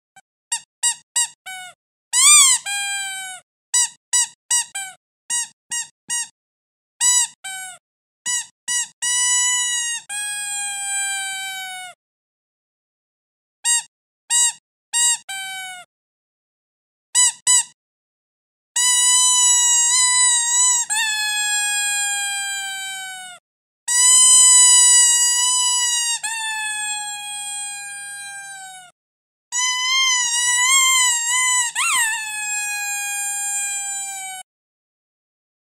Разнообразие звуков пищалок